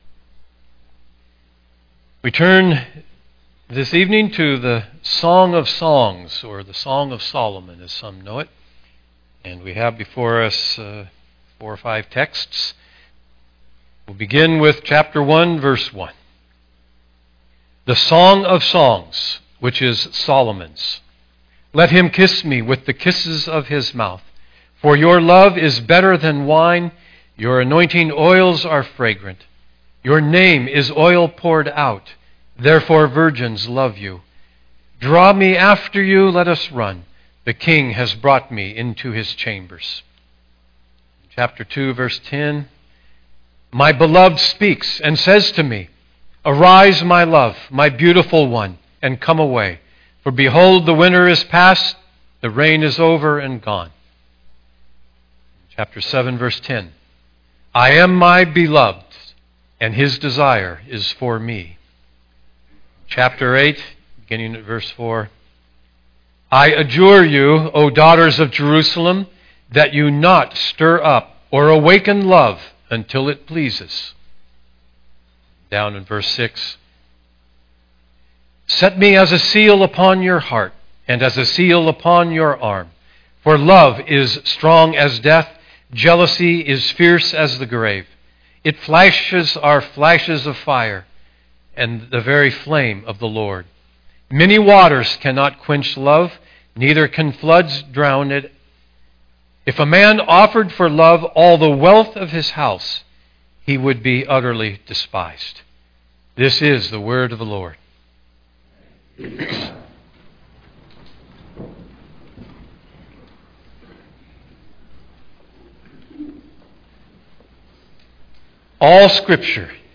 Download Sermon Notes Listen & Download Audio Series